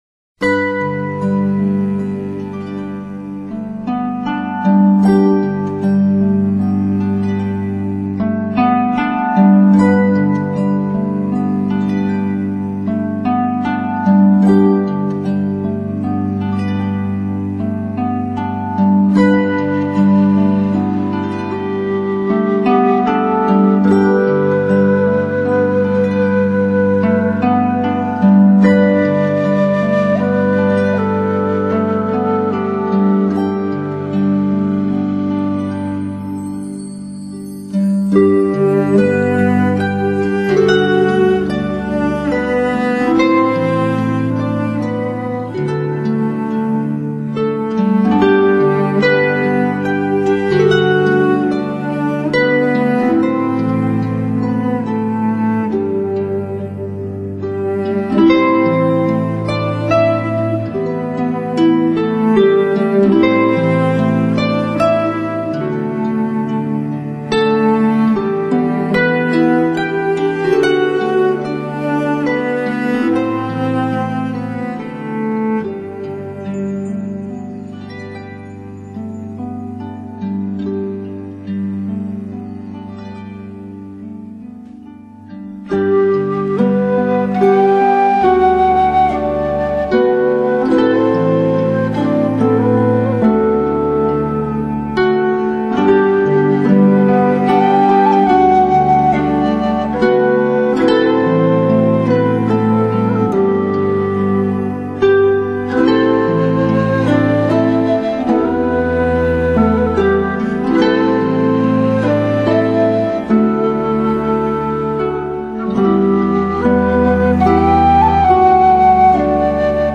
音乐类型：Celtic Newage
琴技法，加上清新的竹笛，时而出现的敲击乐，让你不醉也难。